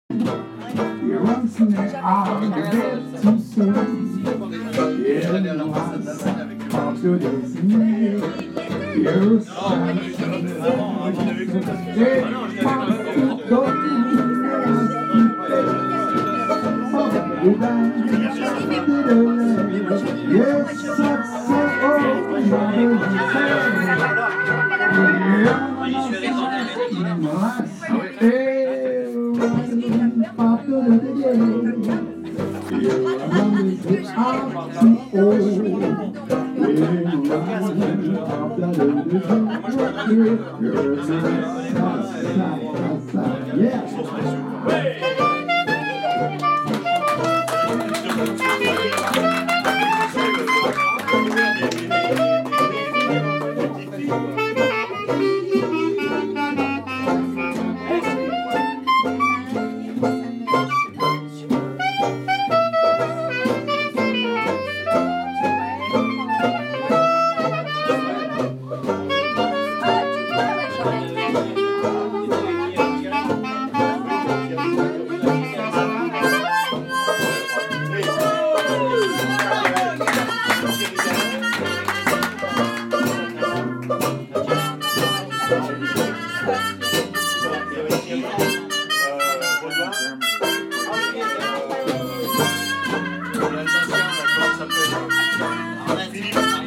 Jazz at Le Petit Journal jazz club St-Michel Paris
Dixieland jazz Paris